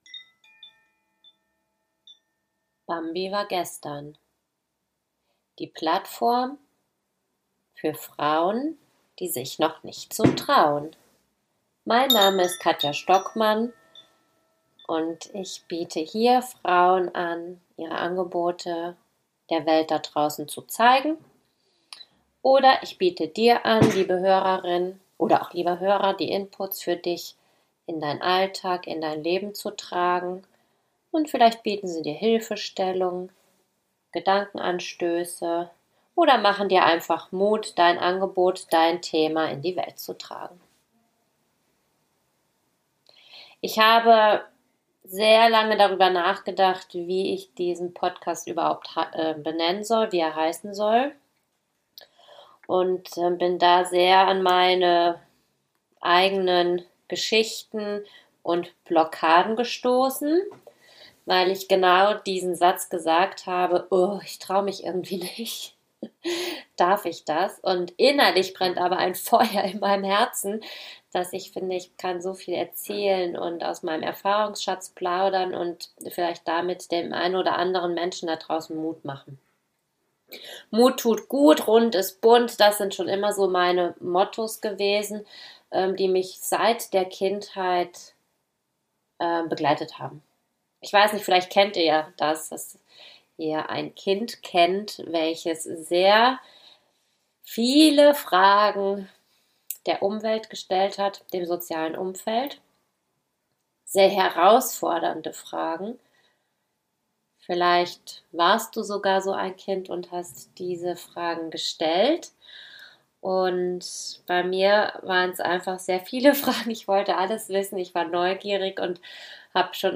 Ich lese einen Auszug aus meinem Buch.